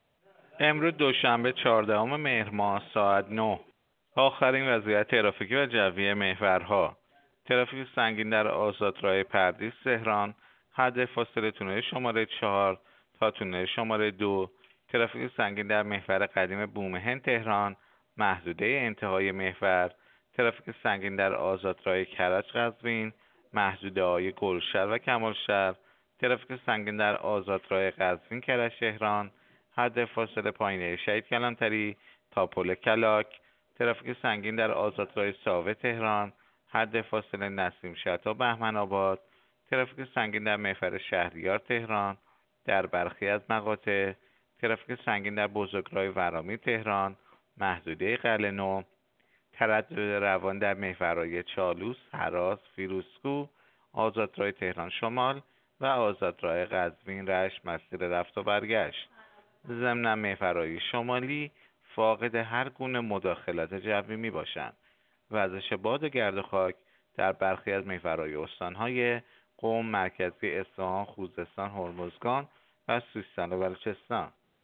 گزارش رادیو اینترنتی از آخرین وضعیت ترافیکی جاده‌ها ساعت ۹ چهاردهم مهر؛